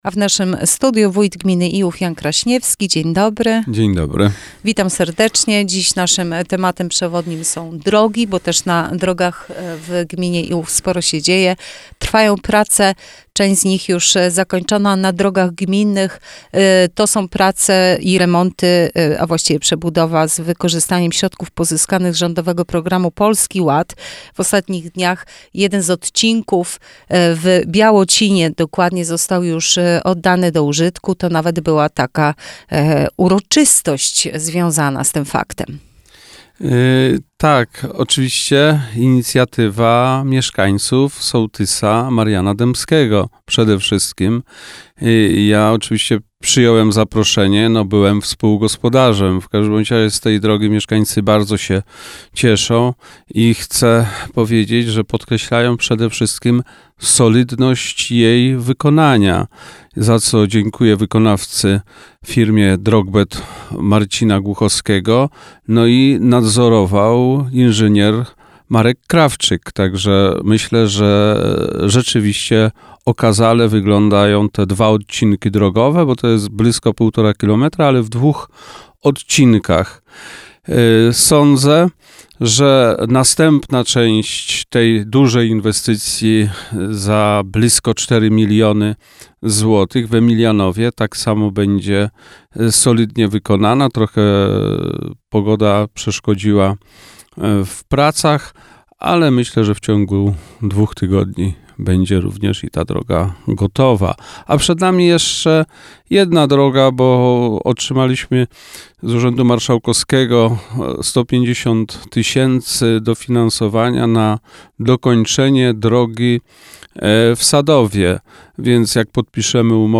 Wywiad z Wójtem Janem Kraśniewskim w Radio Sochaczew - Najnowsze - Gmina Iłów